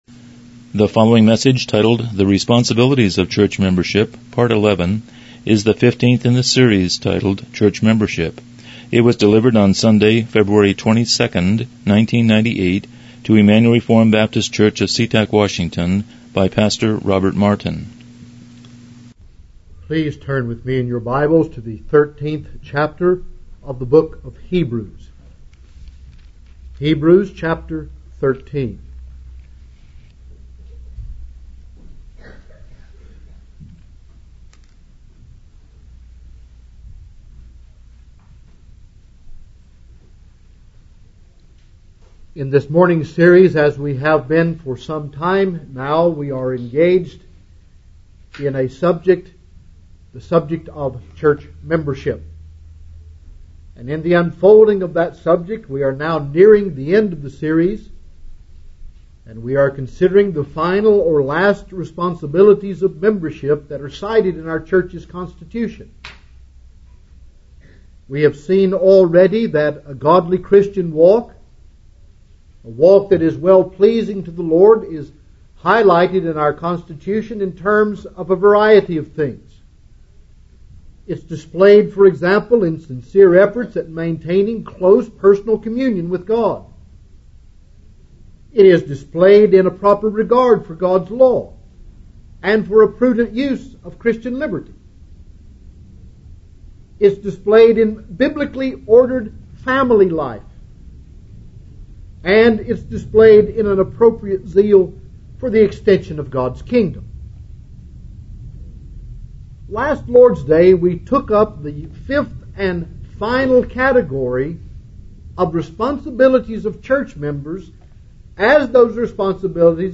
Church Membership Service Type: Morning Worship « 14 Responsibilities of